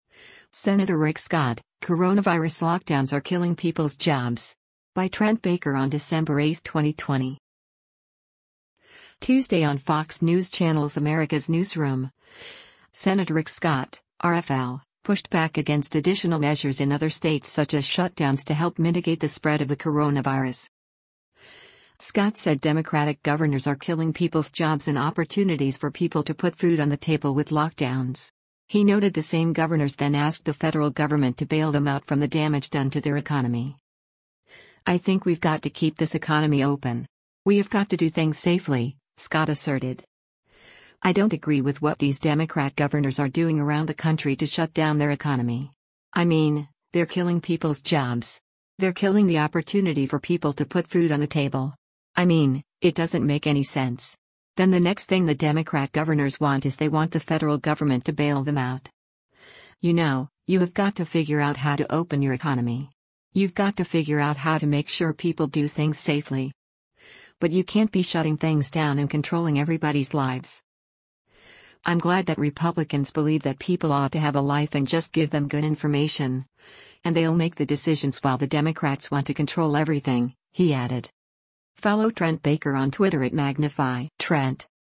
Tuesday on Fox News Channel’s “America’s Newsroom,” Sen. Rick Scott (R-FL) pushed back against additional measures in other states such as shutdowns to help mitigate the spread of the coronavirus.